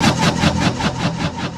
Index of /90_sSampleCDs/Houseworx/12 Vocals